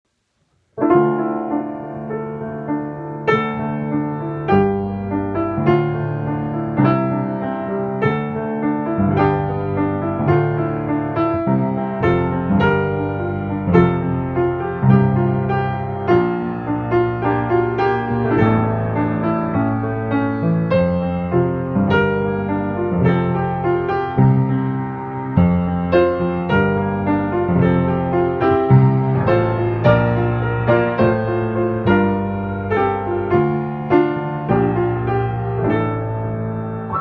Die Vision als Lied:
Klaviersatz